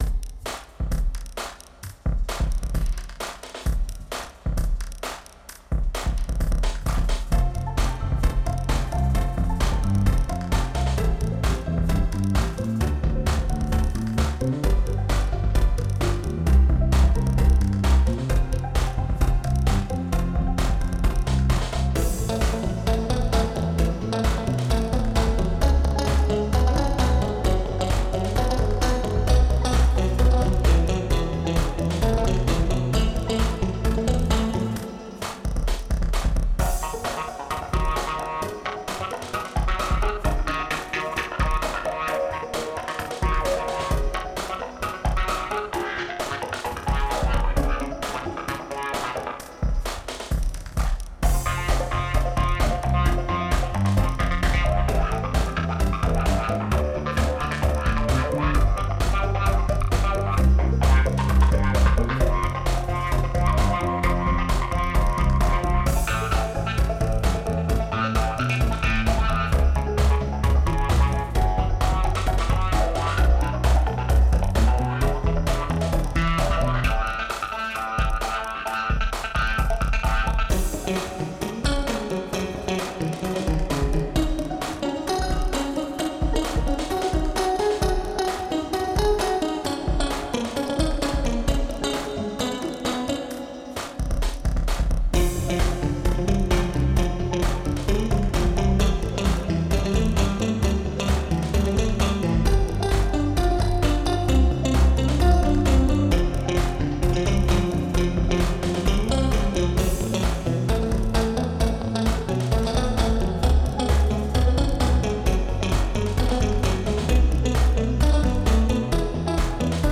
Genre: Hip-Hop